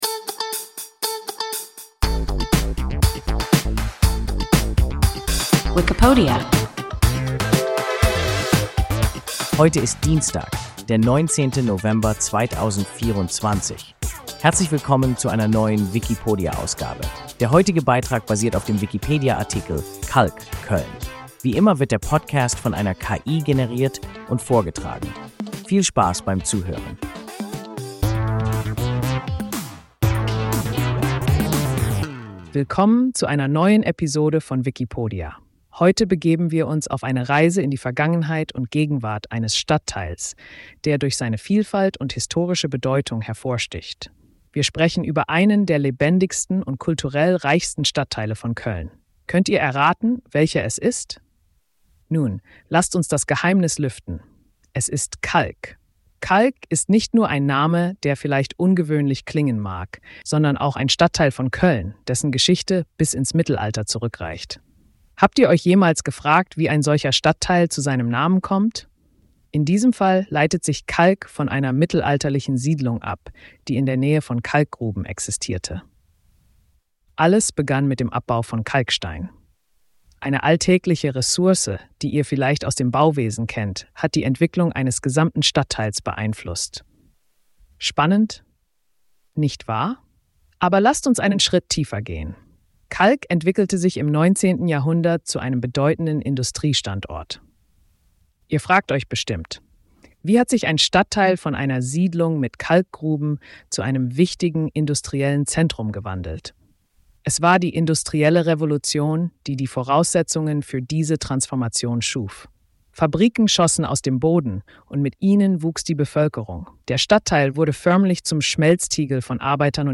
Kalk (Köln) – WIKIPODIA – ein KI Podcast